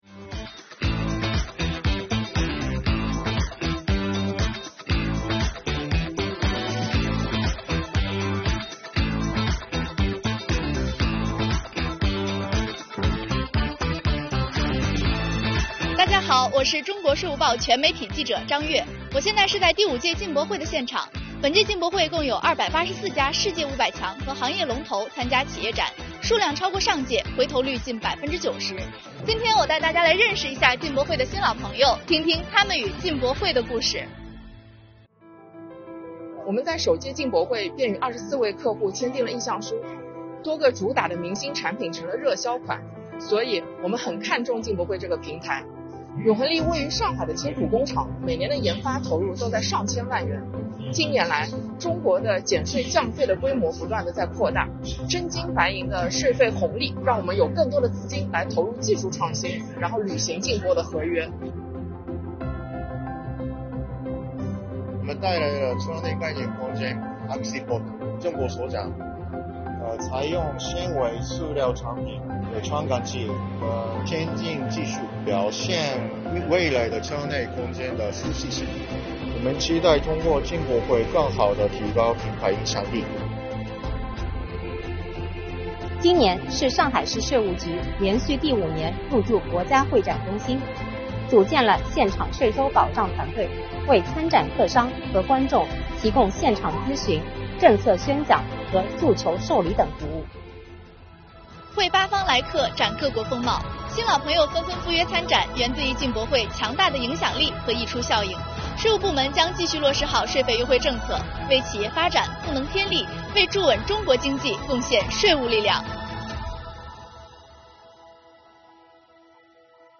探馆Vlog丨记者带你去逛进博会
第五届中国国际进口博览会正在国家会展中心（上海）火热进行中。今天，我们跟随中国税务报全媒体记者一起去探馆，听听新鲜有料的进博故事。